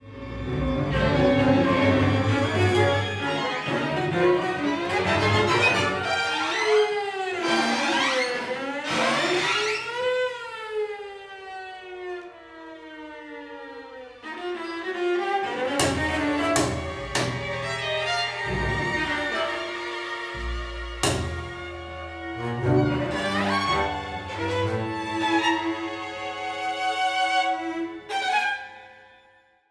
For two string quartets and double bass placed symmetrically
Description:Classical; ensemble music
Instrumentation:string quartet (2), double bass